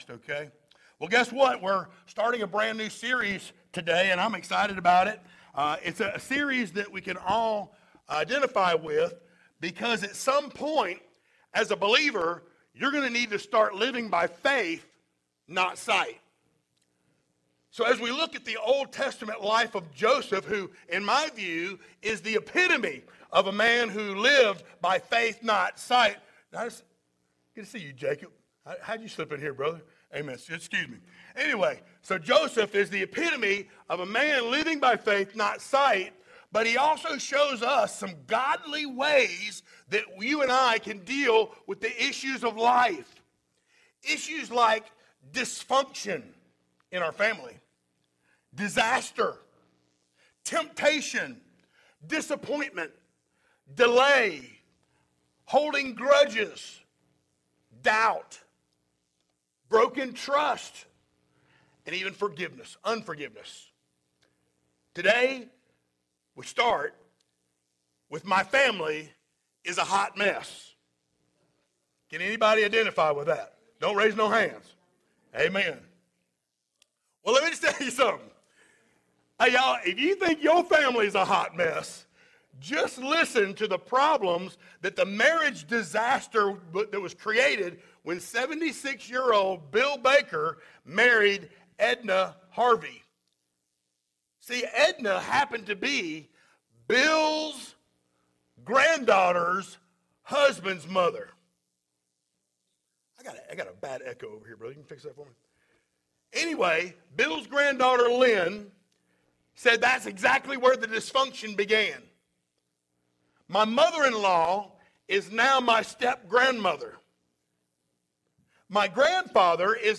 Series: sermons
Genesis 37:1-11 Service Type: Sunday Morning Download Files Notes Topics